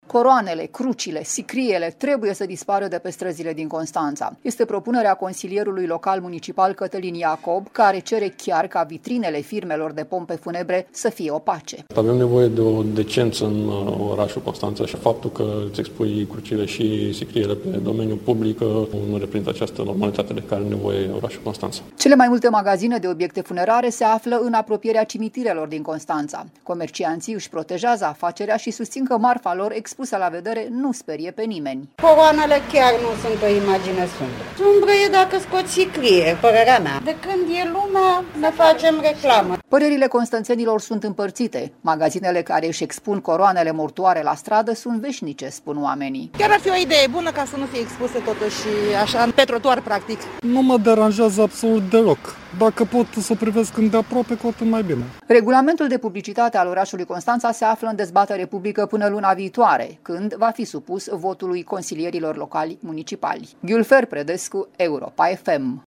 “Chiar ar fi o idee bună, ca să nu fie expuse, totuși, așa, pe trotuar, practic”, declara o femeie.